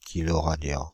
Ääntäminen
France (Île-de-France): IPA: [ki.lɔ.ʁa.djɑ̃]